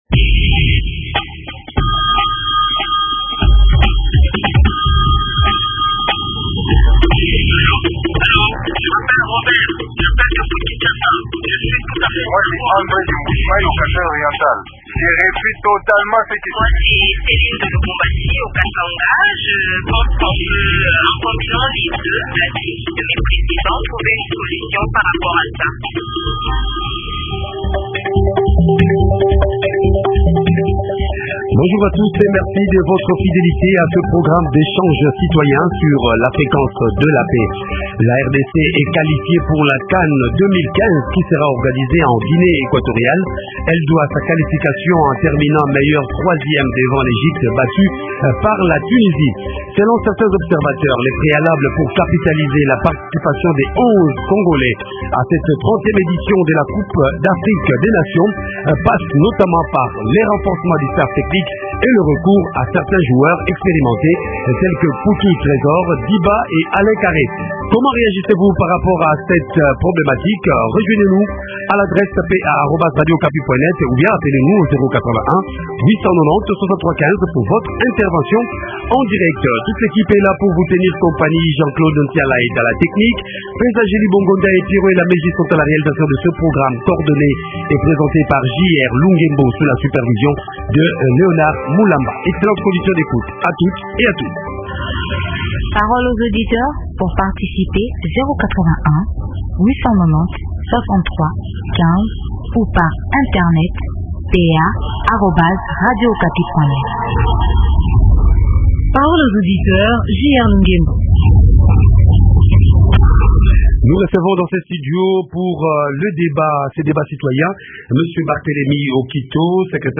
Invité: Barthélemy Okito, Secrétaire Général au Ministère de la Jeunesse, Sport et Loisirs